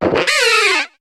Cri de Chaffreux dans Pokémon HOME.